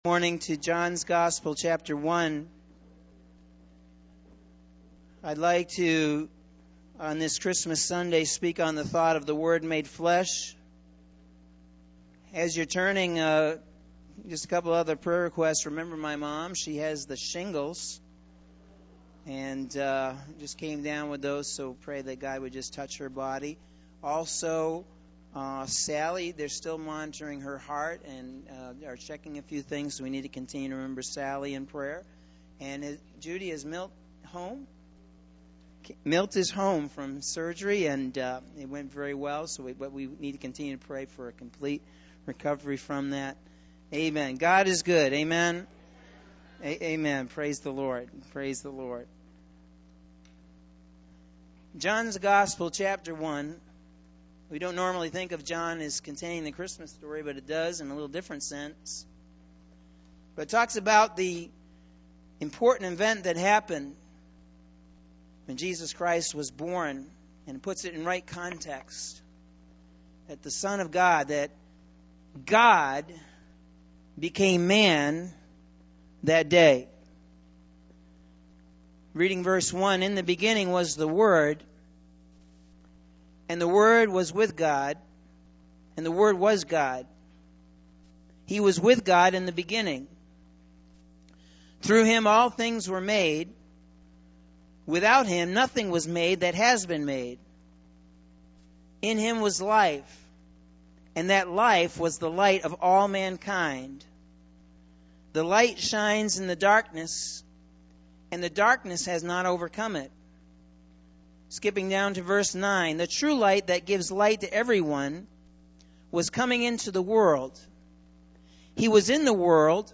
Sunday December 19th – AM Sermon – Norwich Assembly of God